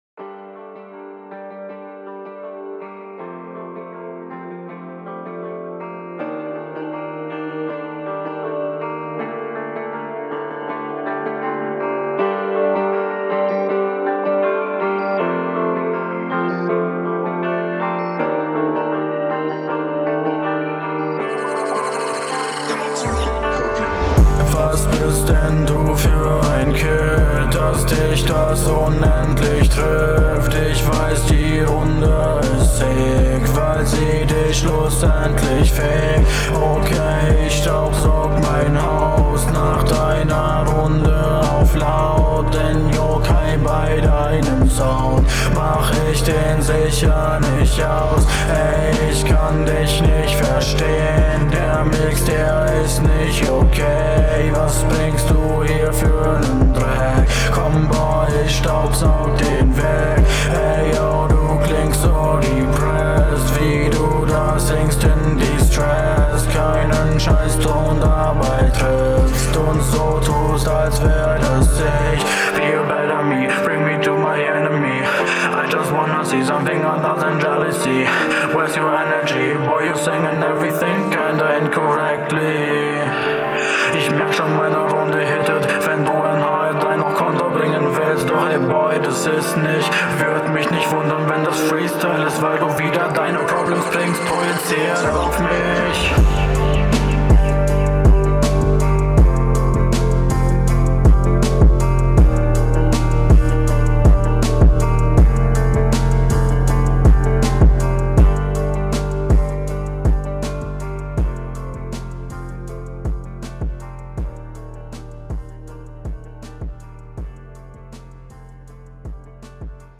Also das Autotune passt nicht so ganz auf deine Stimme bzw ist das Komisch eingestellt, …